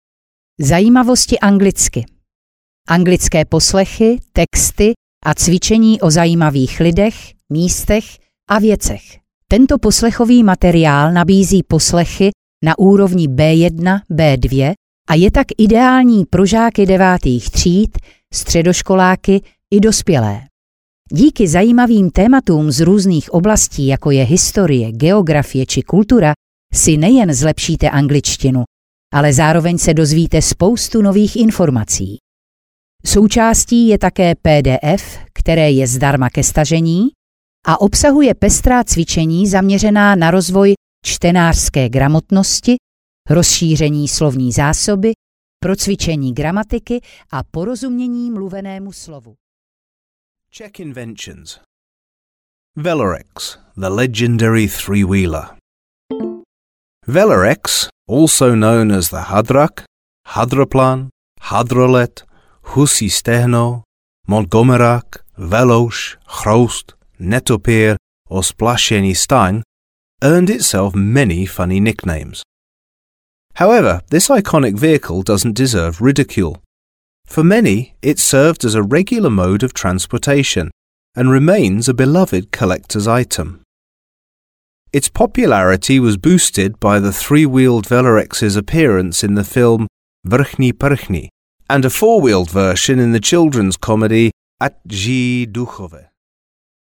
Ukázka z knihy
zajimavosti-anglicky-audiokniha